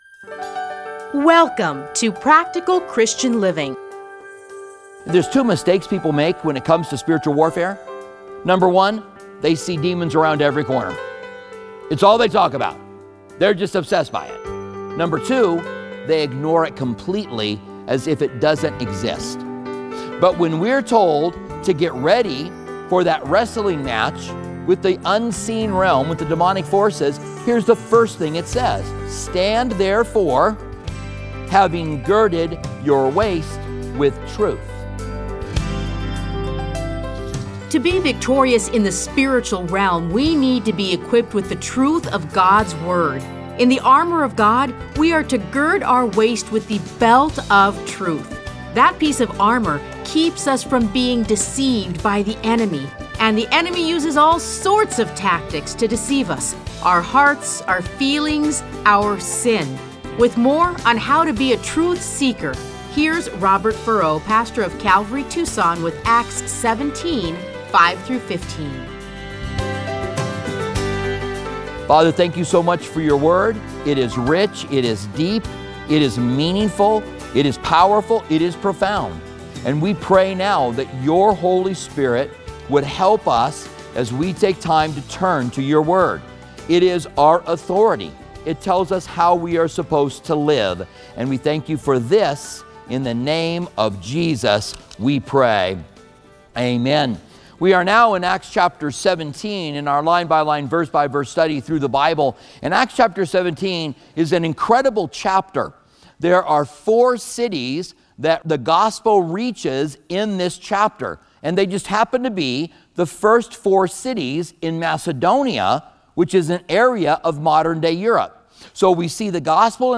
Listen to a teaching from Acts 17:5-15.